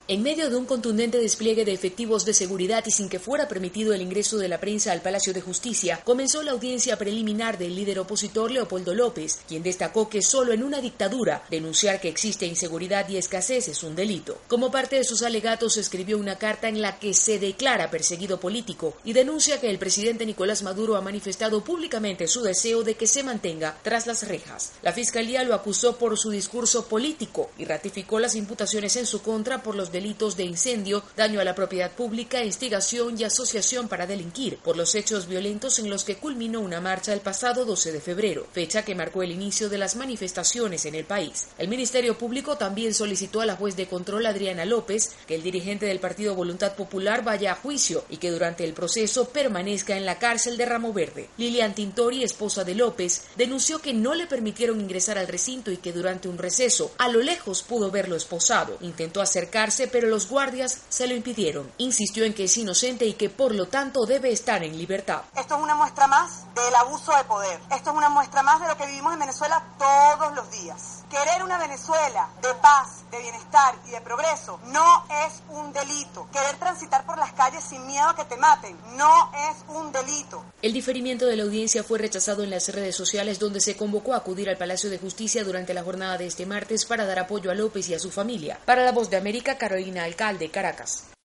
informa desde Caracas.